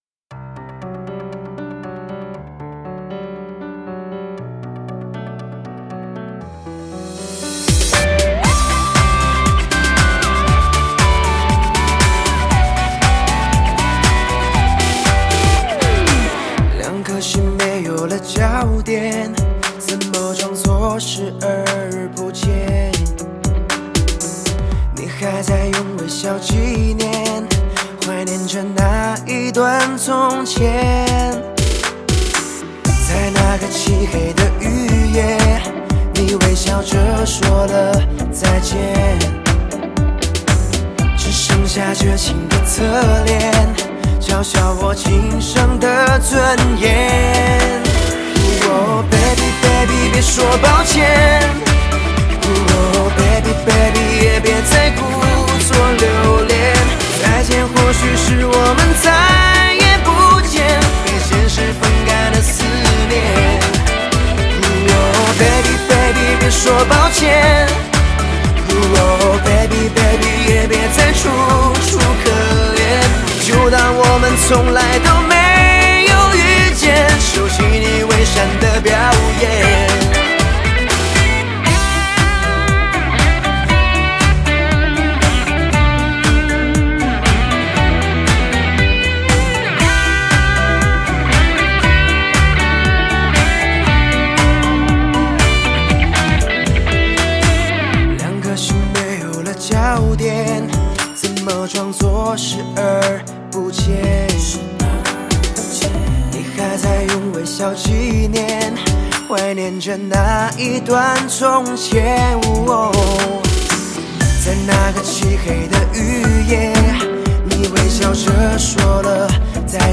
曲风：HIP-HOP DJ/舞曲